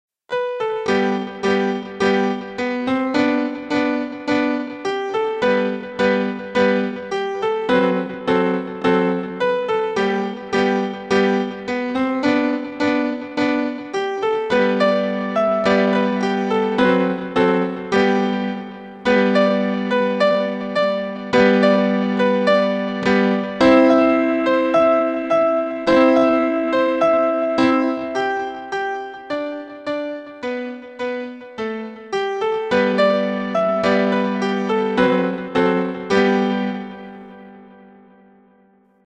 小学校や中学校の運動会やレクリエーションなどでよく使われるフォークダンス音楽です。
明るく躍動感のあるメロディであり、楽しい雰囲気を醸し出します。